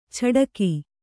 ♪ chaḍaki